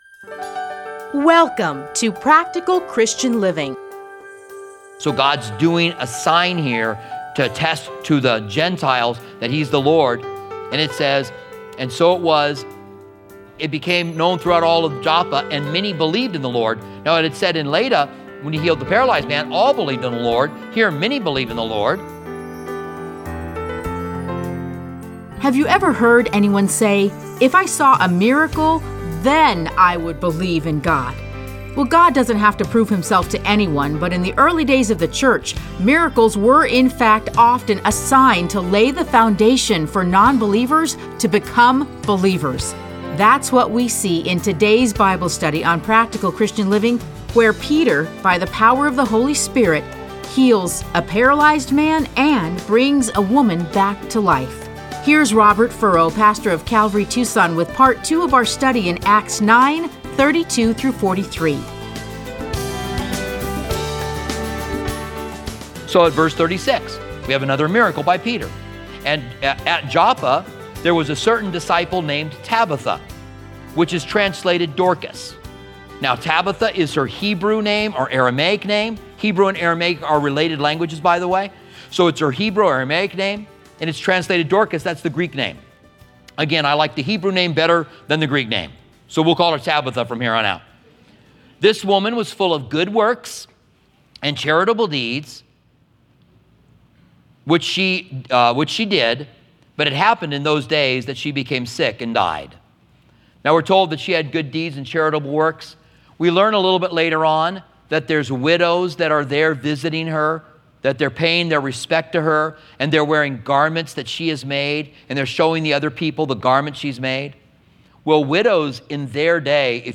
Listen to a teaching from Acts 9:32-43.